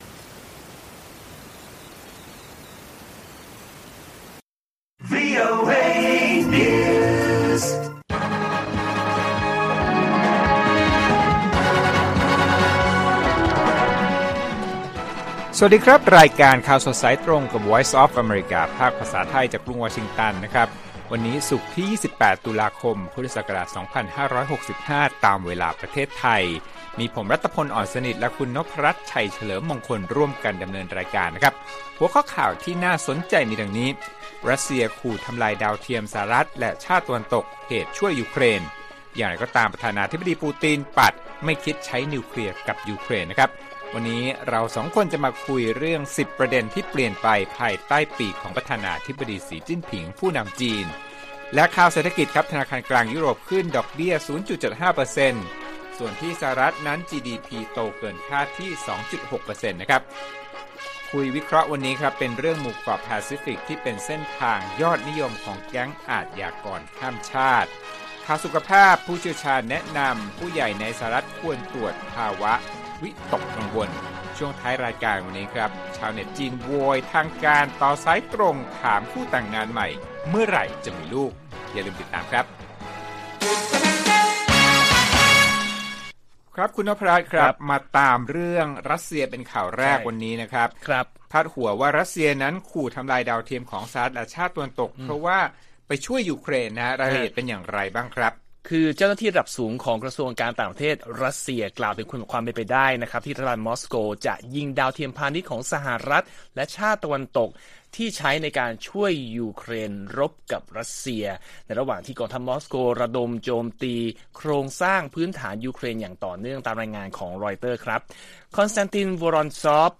ข่าวสดสายตรงจากวีโอเอไทย 6:30 – 7:00 น. วันที่ 28 ต.ค. 65